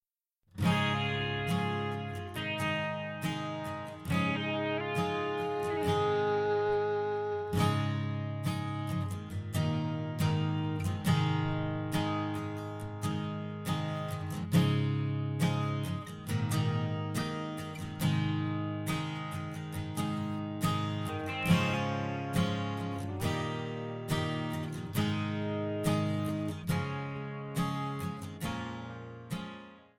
Db
MPEG 1 Layer 3 (Stereo)
Backing track Karaoke
Country, 2000s